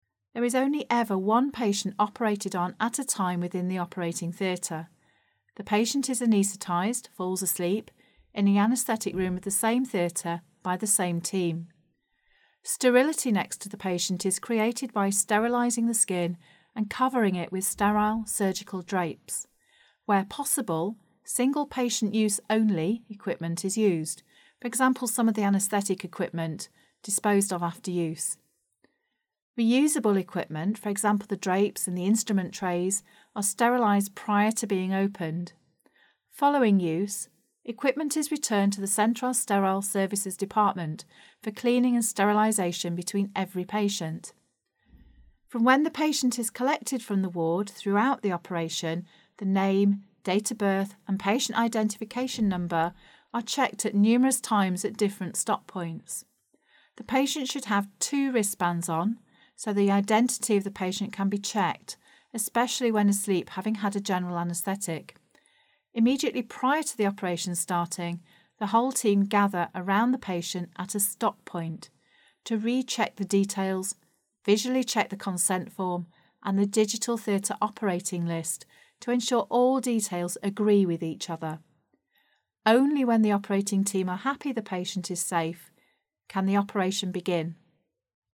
Read Aloud